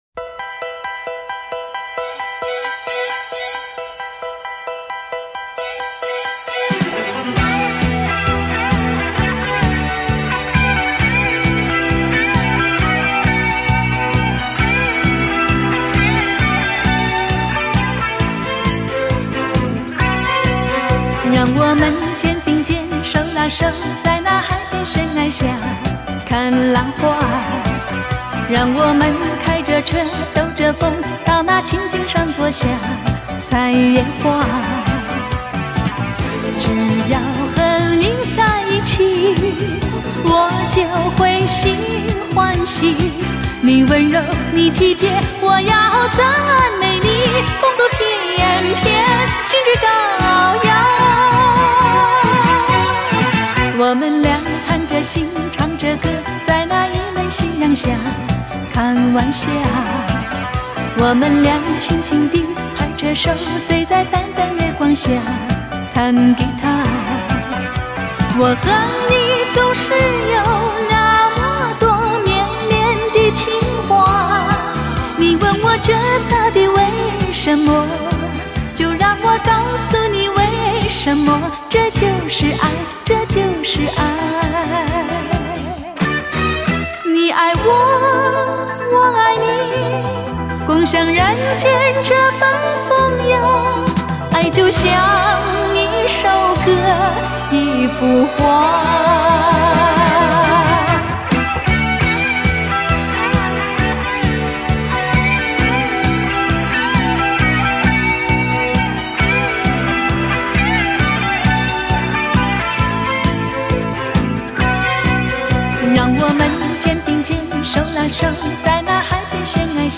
DXD重新编制